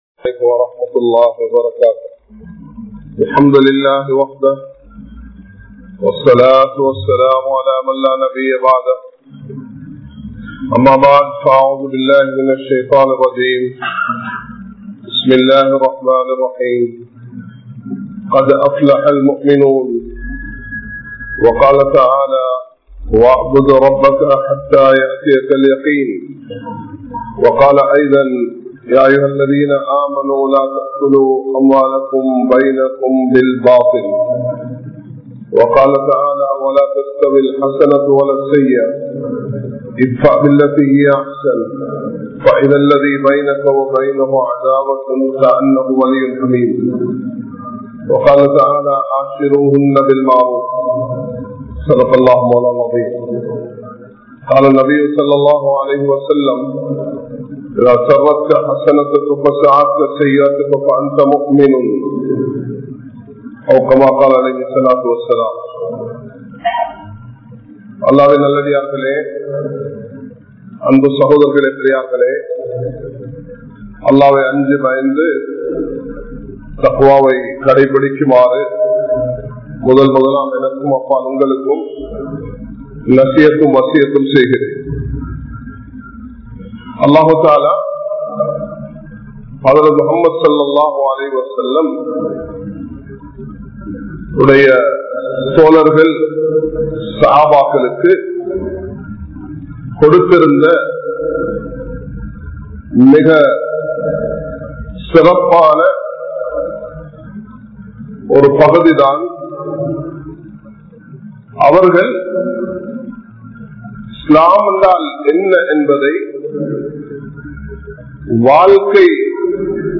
Shahabaakkalin Panpuhal (ஸஹாபாக்களின் பண்புகள்) | Audio Bayans | All Ceylon Muslim Youth Community | Addalaichenai
Majma Ul Khairah Jumua Masjith (Nimal Road)